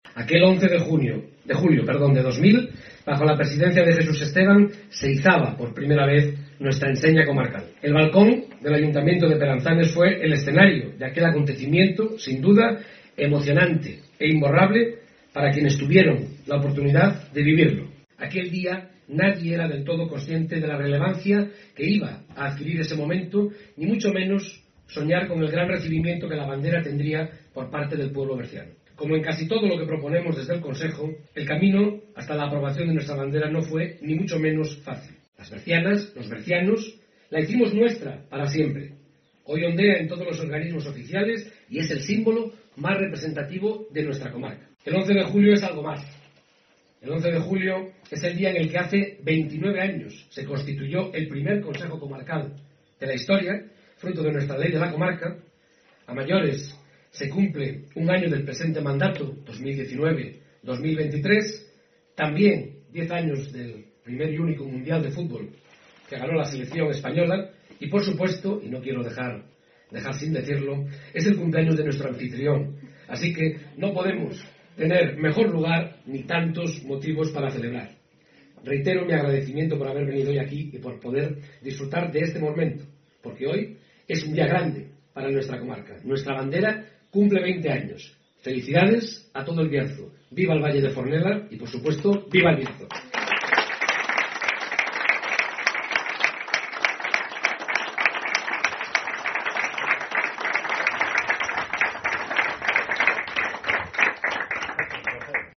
Como en aquella ocasión el escenario ha sido el Ayuntamiento de Peranzanes
Escucha aquí las palabras del presidente del Consejo Comarcal del Bierzo, Gerardo Álvarez Courel.